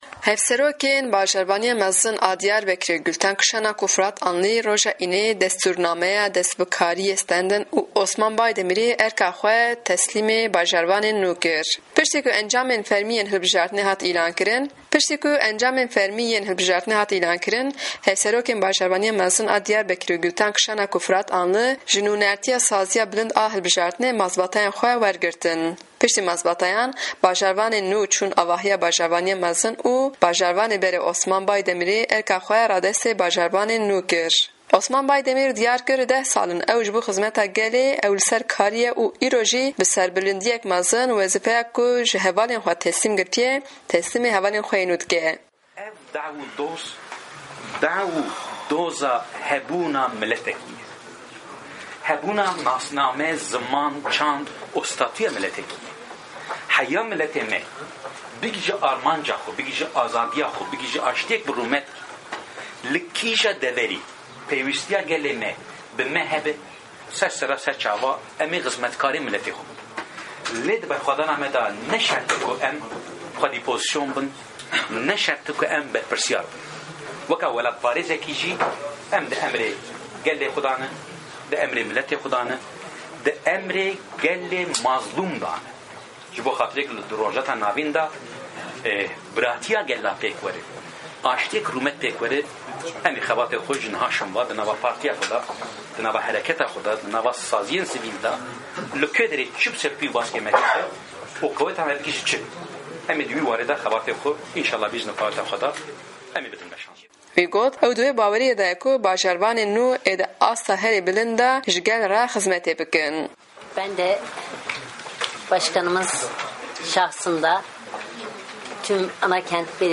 Raporta Diyarbekirê